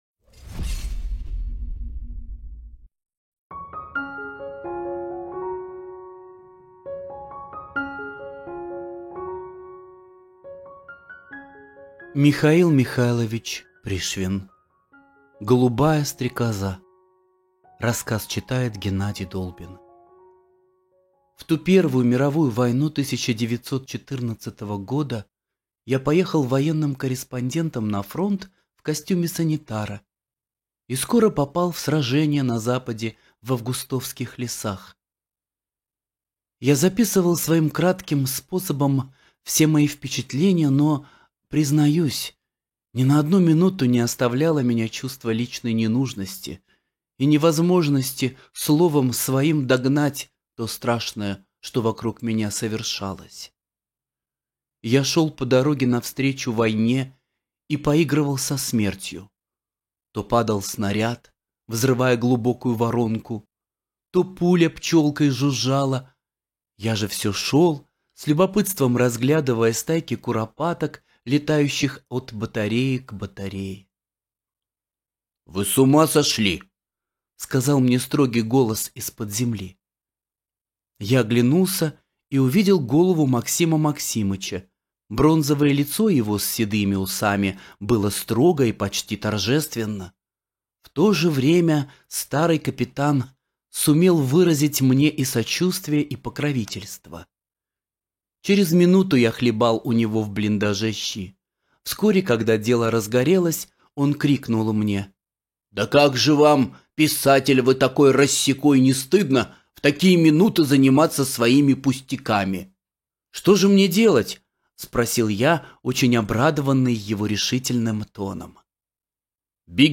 Голубая стрекоза – Пришвин М.М. (аудиоверсия)
Аудиокнига в разделах